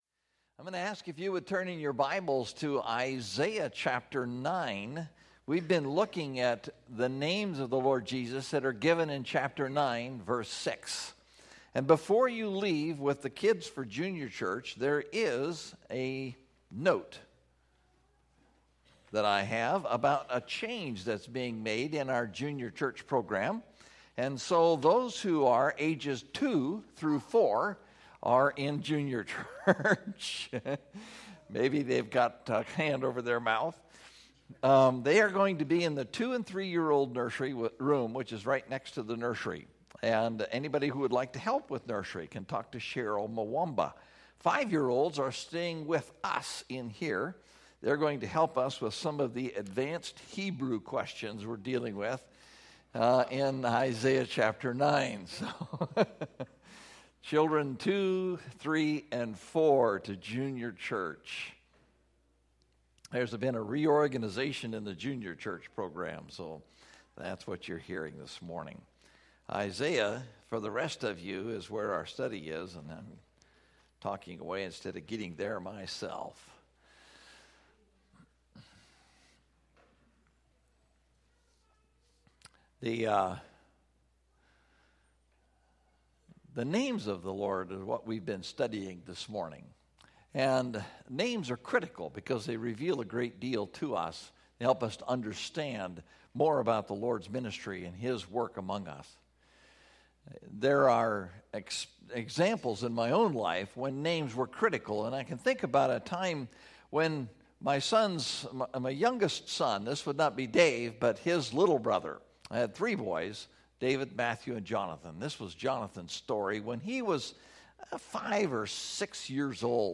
Everlasting Father (Isaiah 9:6) – Mountain View Baptist Church